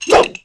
wrench_alt_fire3.wav